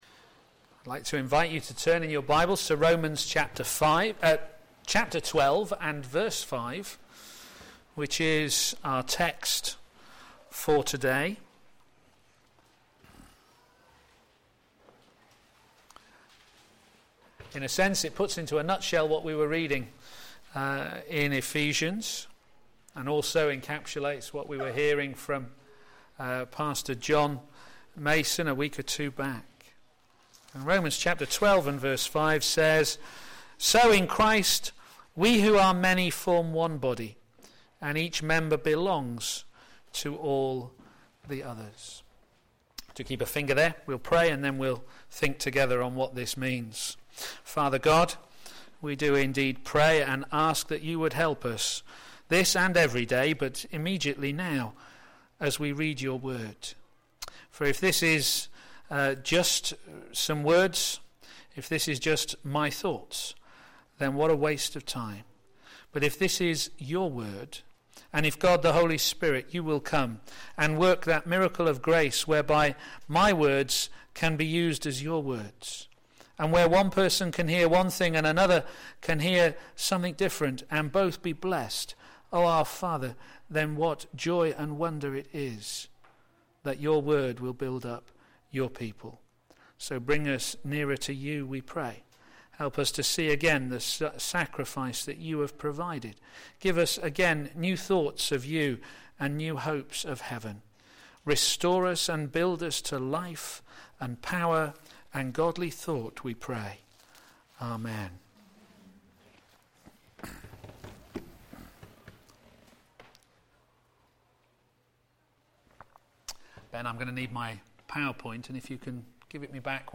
Media Library Media for a.m. Service on Sun 09th Mar 2014 10:30 Speaker
Romans 12 Series: Love is all you need? Theme: Be at peace Sermon